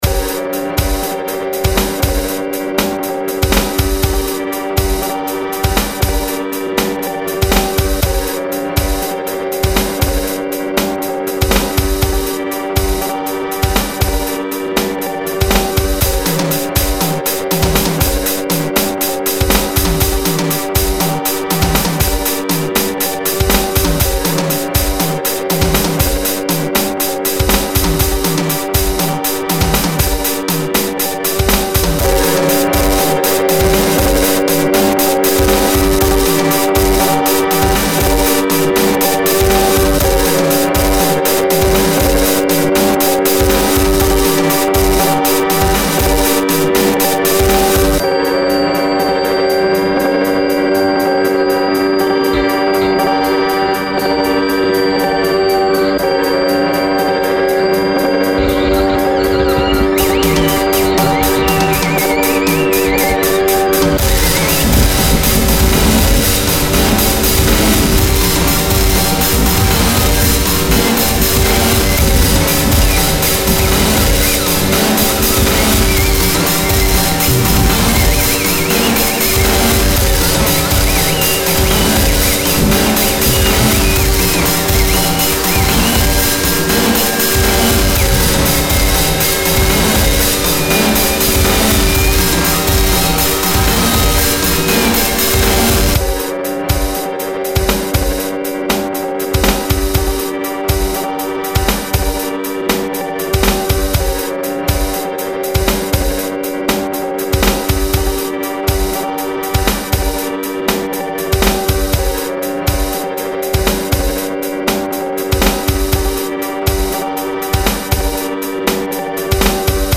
samples, enregistrements, guitare, production & mixage.